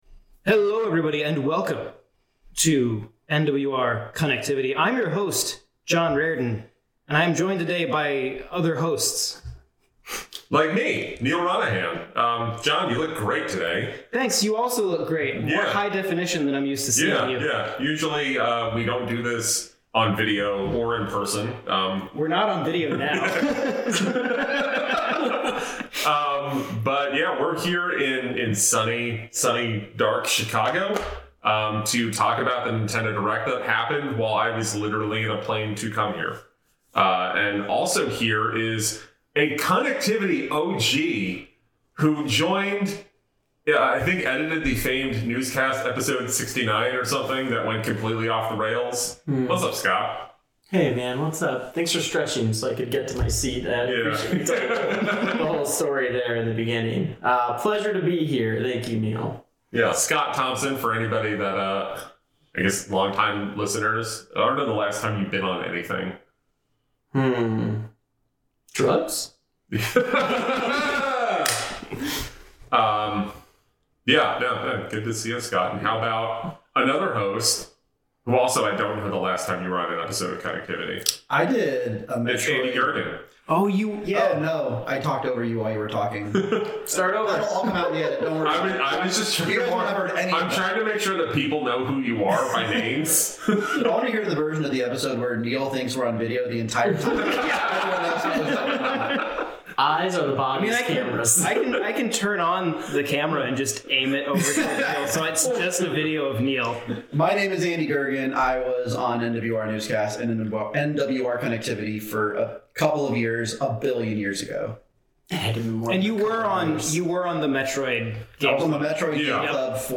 After a fissure in space time opened in Chicago, multiple eras of Connectivity hosts were pulled together into an Air B&B. Then completely unrelated Nintendo decided to have a Nintendo Direct so we quickly and poorly recorded a podcast with whatever we had sitting around. Nobody edited this dumpster fire.